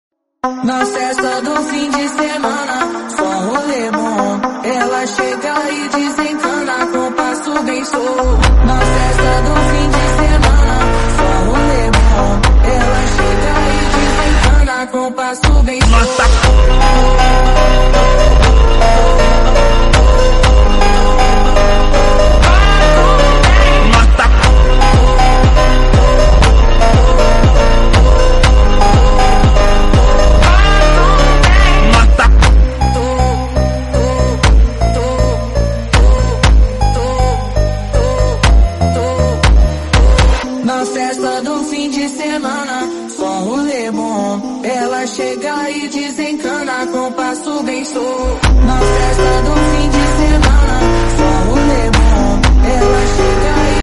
powerful bass